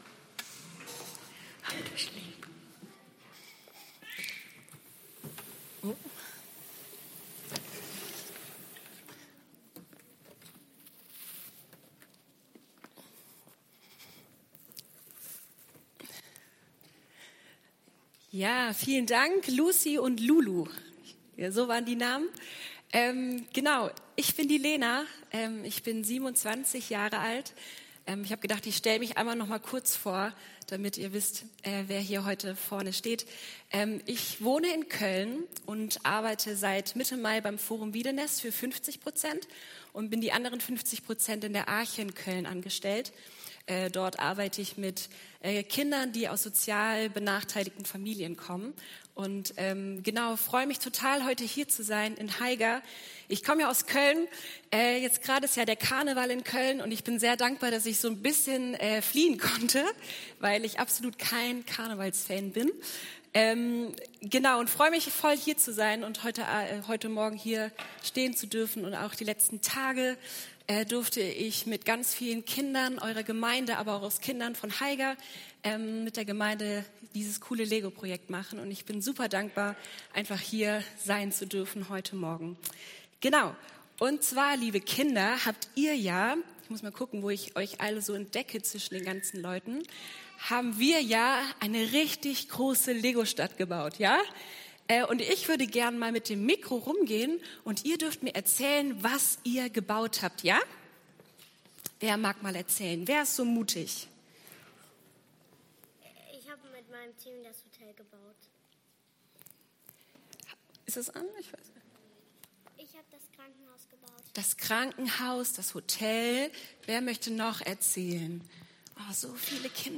Auf was baust du dein Leben? ~ EFG-Haiger Predigt-Podcast Podcast